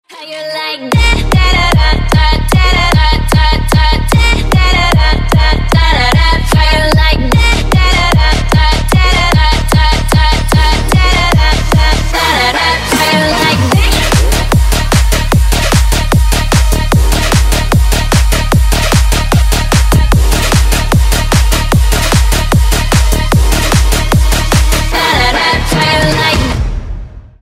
Громкие Рингтоны С Басами
Рингтоны Ремиксы » # Рингтоны Электроника